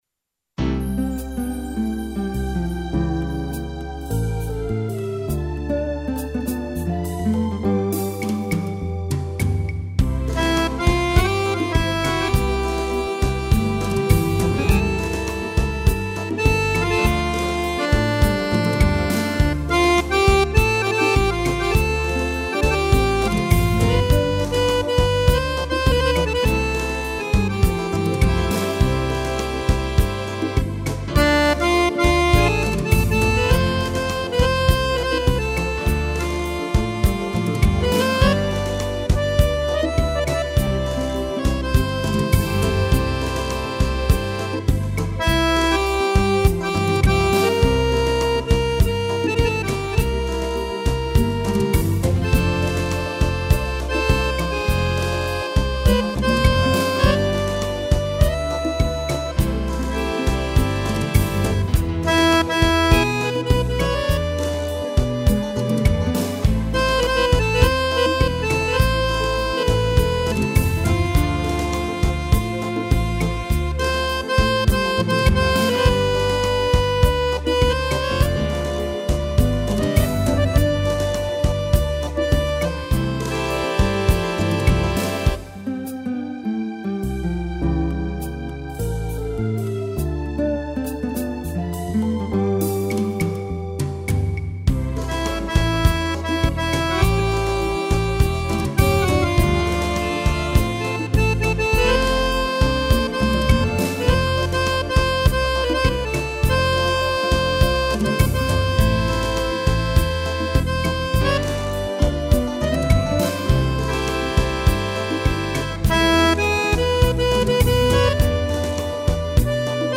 arranjo e interpretação teclado